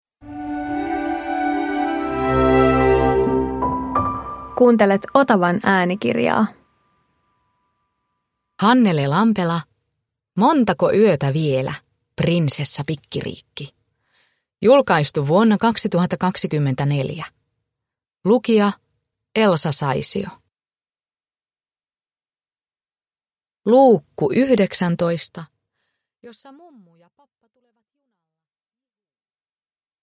Montako yötä vielä, Prinsessa Pikkiriikki 19 – Ljudbok
Uppläsare: Elsa Saisio